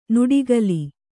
♪ nuḍigali